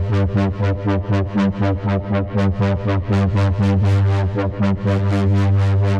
Index of /musicradar/dystopian-drone-samples/Tempo Loops/120bpm
DD_TempoDroneA_120-G.wav